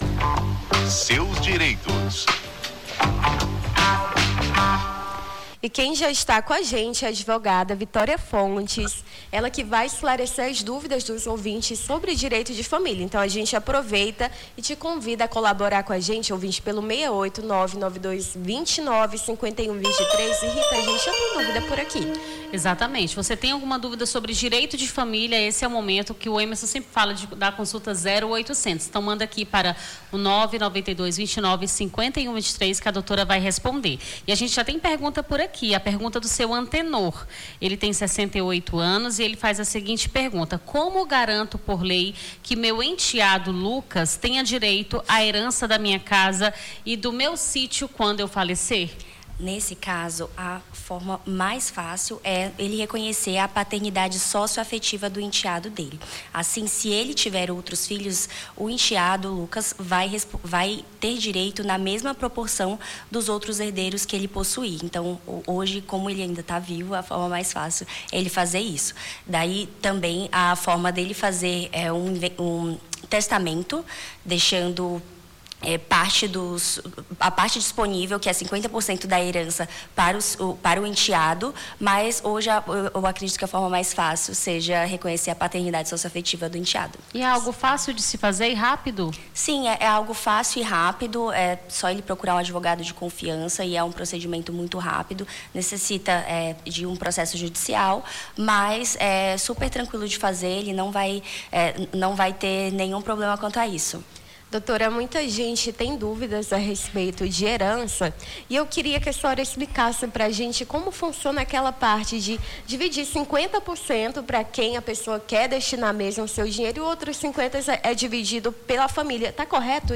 Seus Direitos: advogada tira dúvidas sobre direito de família